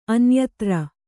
♪ anyatra